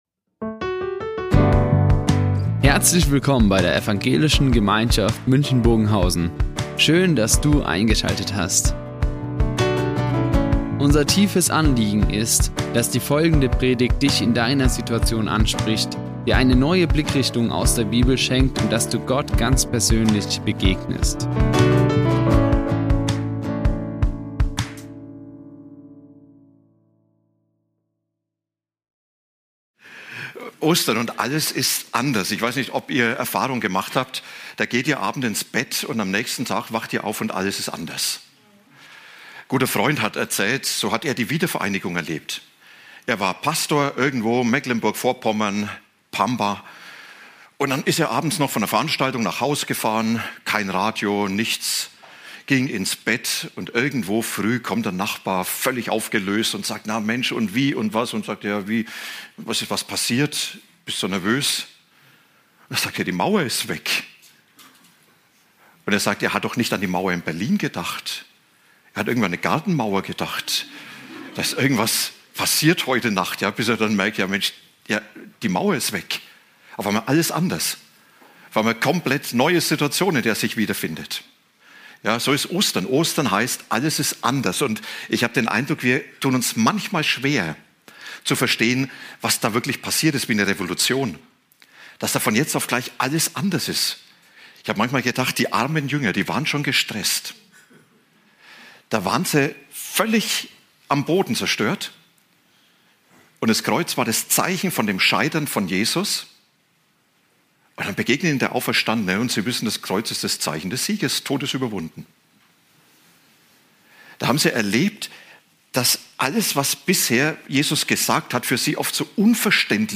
Ostern - und alles ist anders | Predigt 1.Korinther 15, 19-28 ~ Ev. Gemeinschaft München Predigten Podcast